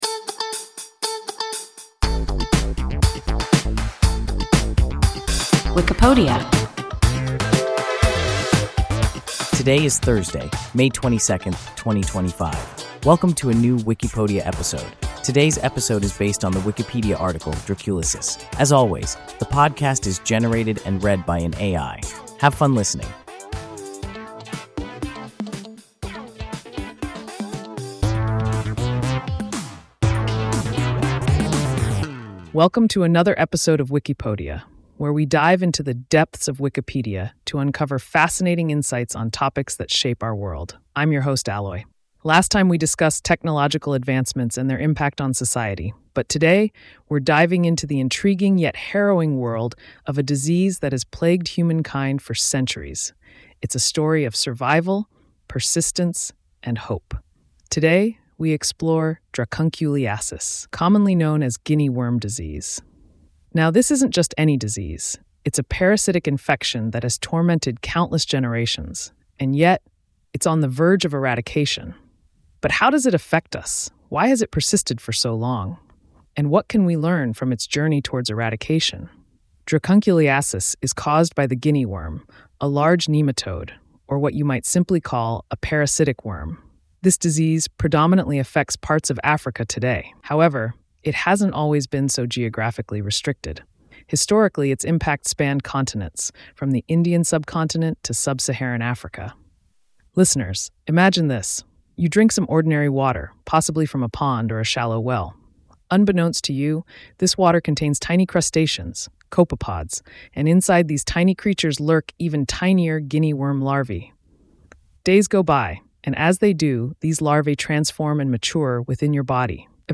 Dracunculiasis – WIKIPODIA – ein KI Podcast